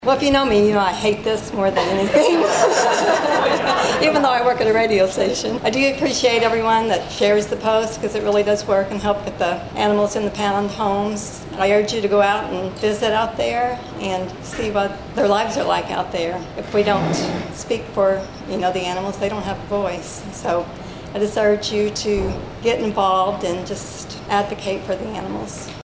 Citizen of the Year, an award handed out at the Carmi Chamber of Commerce annual dinner for 65 years has been won by folks from a variety of walks of life with a variety of areas of expertise.